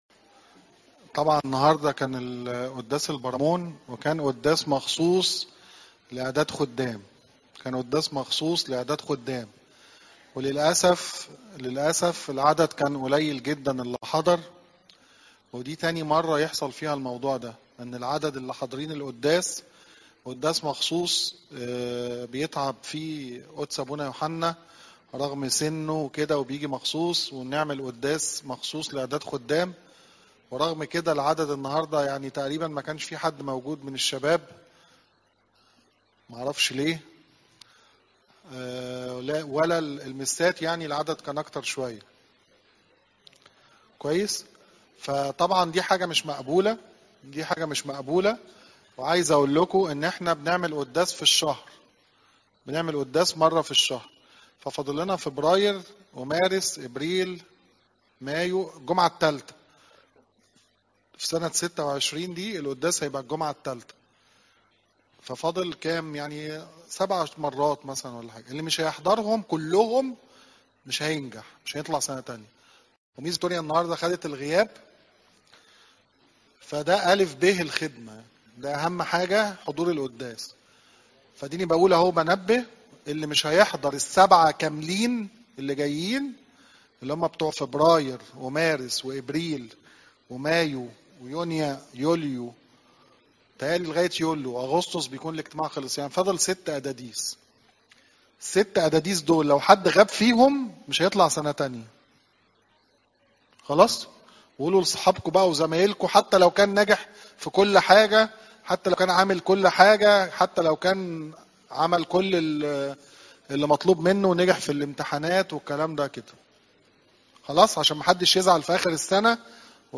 عظات المناسبات